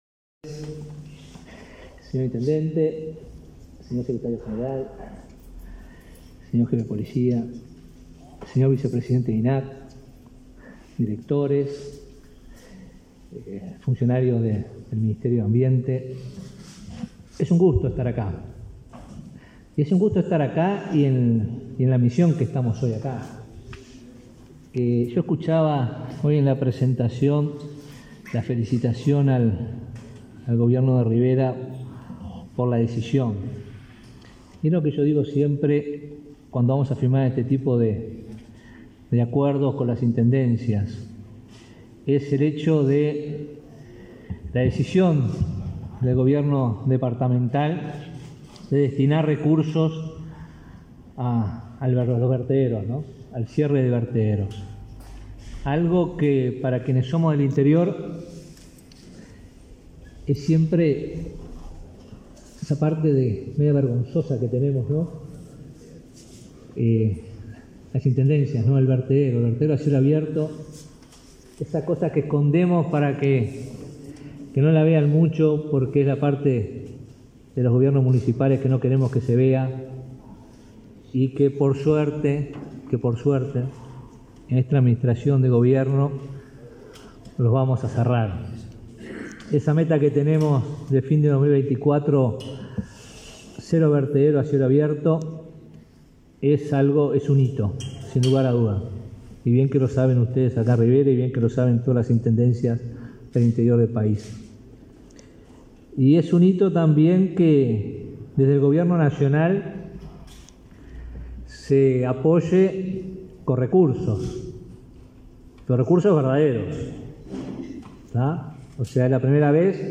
Palabras del ministro de Ambiente, Robert Bouvier
El ministro de Ambiente, Robert Bouvier, asistió, este 27 de julio, al departamento de Rivera para firmar un convenio con las autoridades